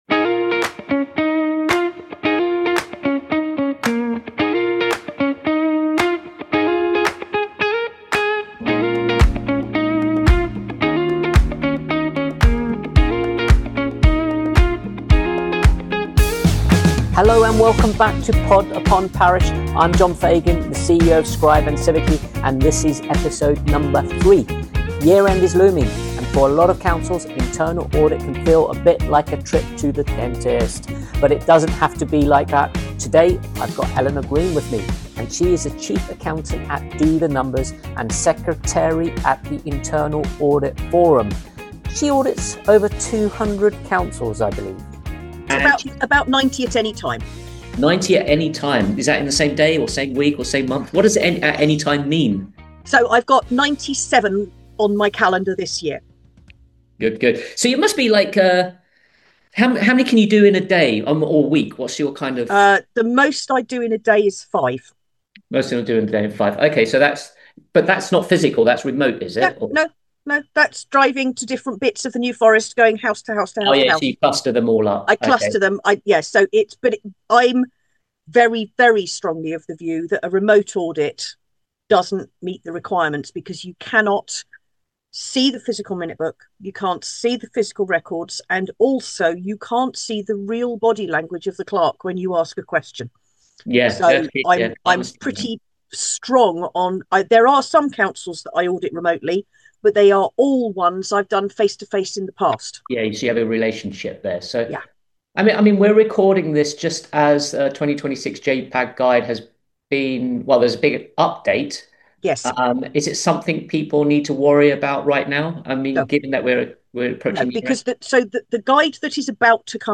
This was recorded as a dedicated podcast conversation rather than a webinar conversion, so it's a more intimate, back-and-forth discussion.